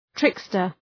{‘trıkstər}